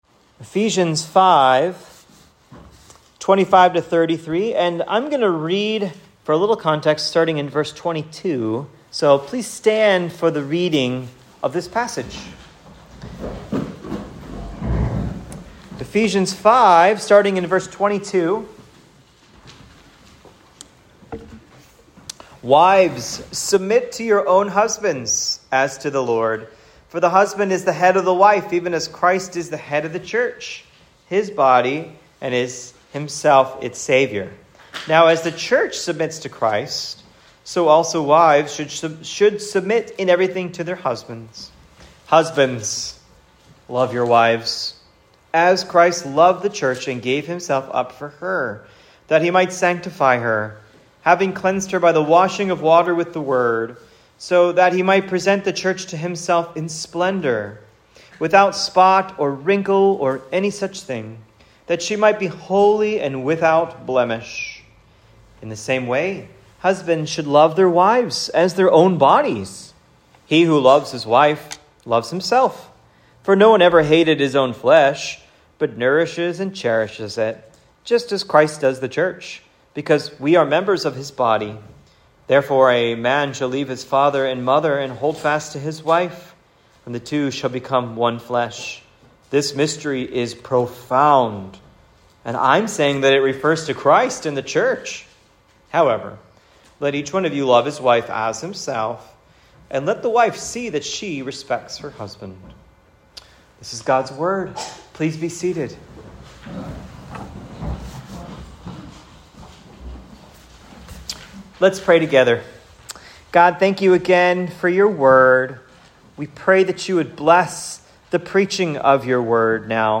Christians Husbands: LOVE Your Wives (Ephesians 5:25-33 SERMON)